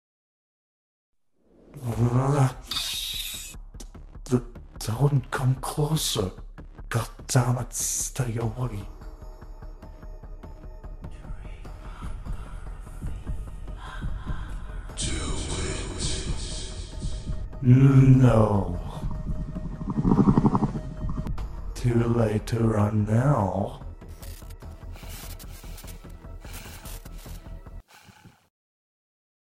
ALSO i also did the whispers in the backround myself